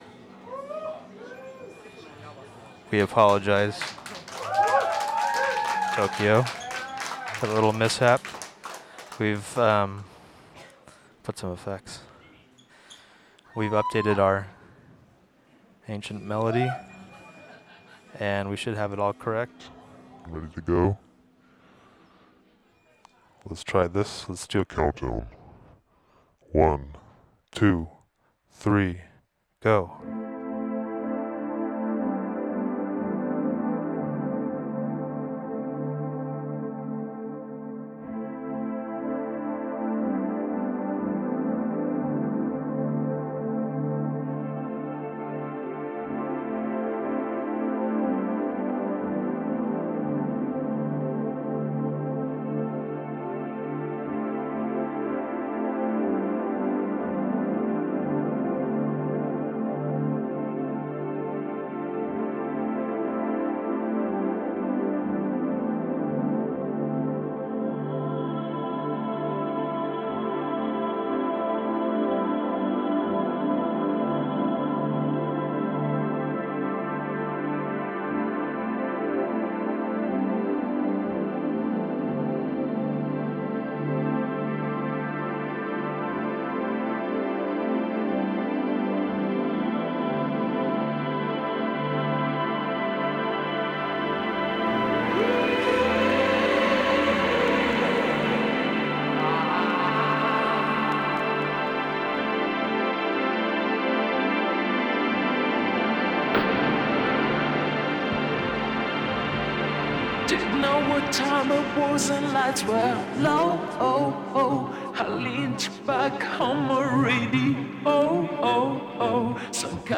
Mysterious Ancient Melodies
recorded live and in-studio simultaneously (!!!)